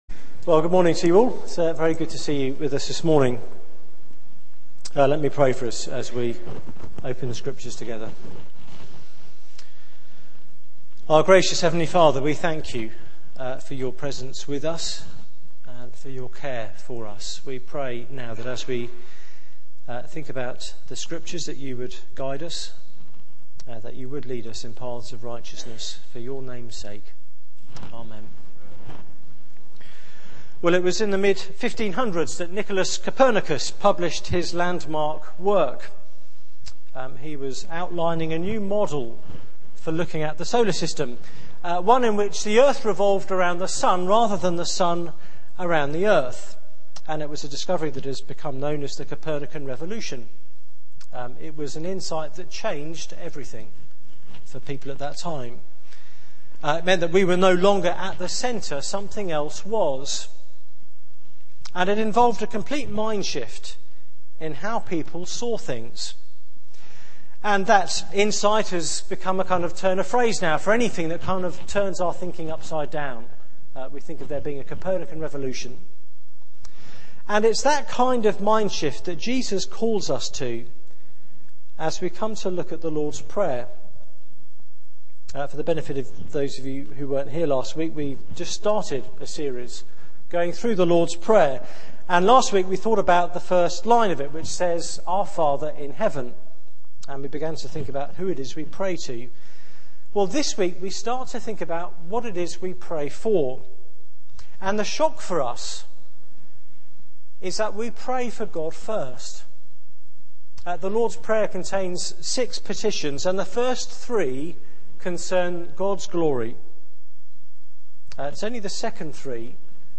Media for 9:15am Service
Theme: 'Hallowed be Your Name' Sermon